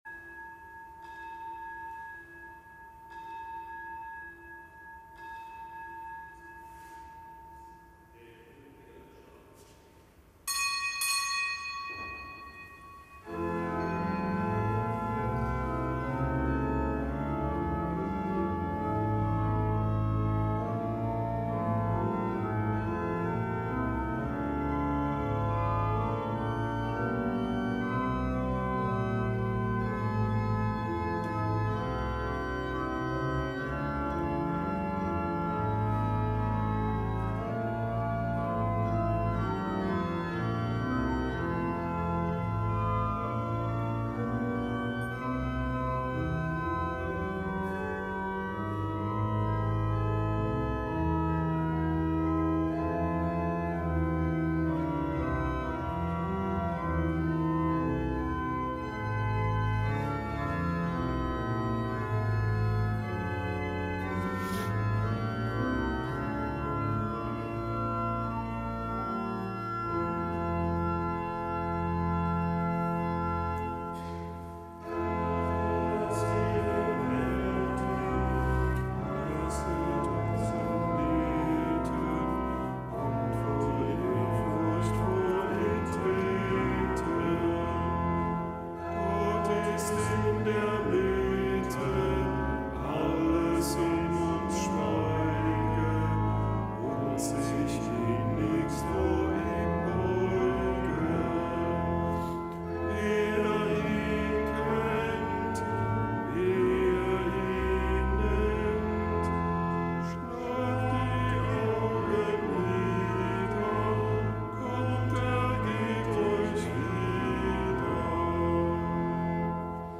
Kapitelsmesse aus dem Kölner Dom am Mittwoch der dritten Woche im Jahreskreis; Zelebrandt: Weihbischof Ansgar Puff.